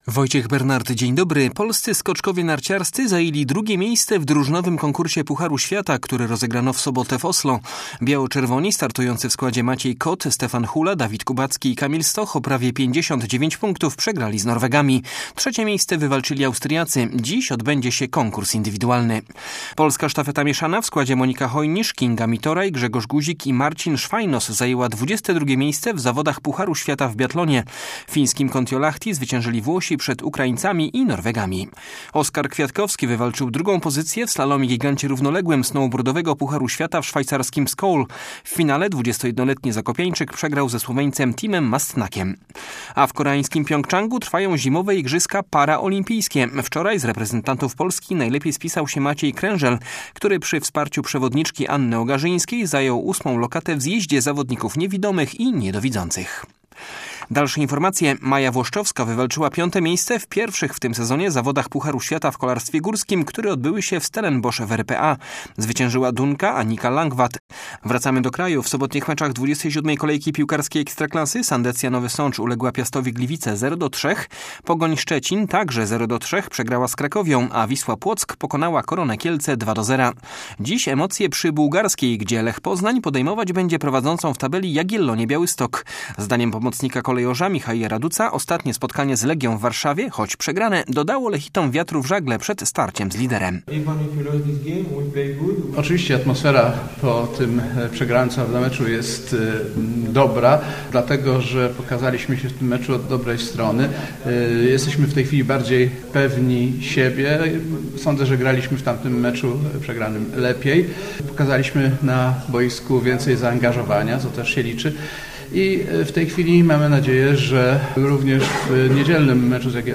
11.03 serwis sportowy godz. 9:05